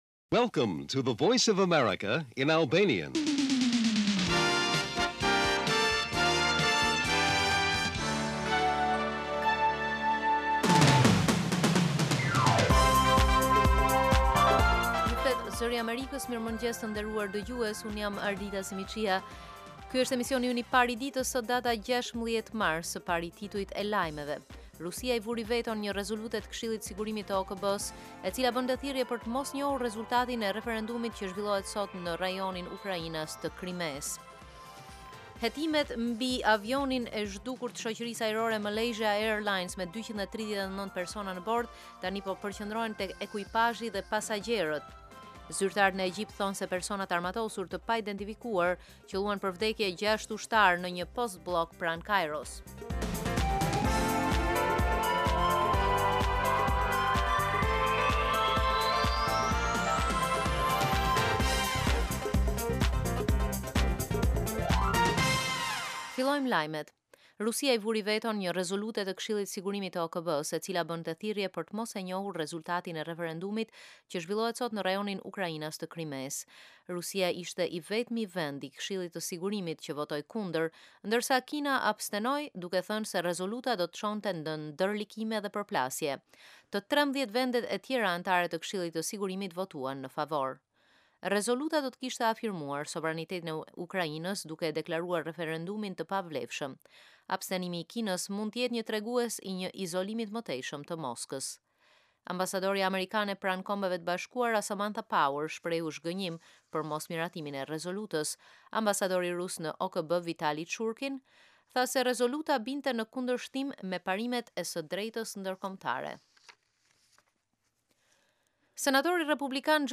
Lajmet e mëngjesit